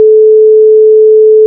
Daraus berechnet sich nun ausgehend von 440Hz die neue Abtastrate:
» Download der bearbeiteten Datei mit 11kHz:
ton16khzfabt-11khz.wav